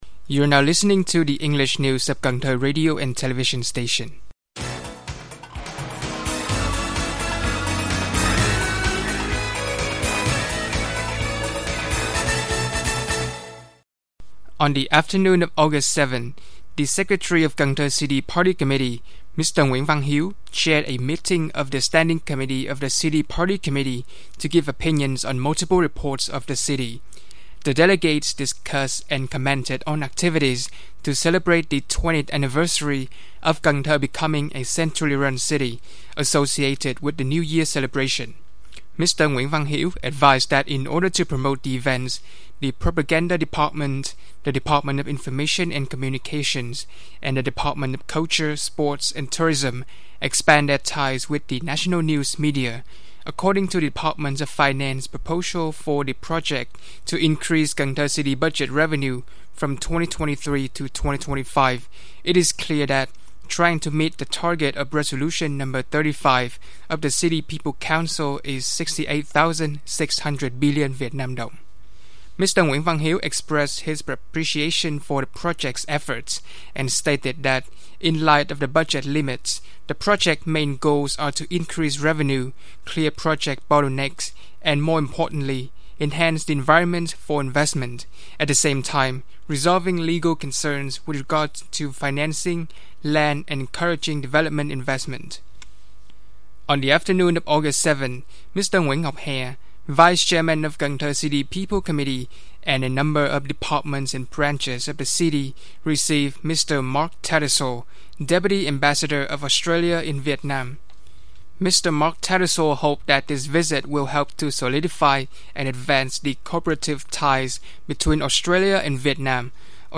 Bản tin tiếng Anh 8/8/2023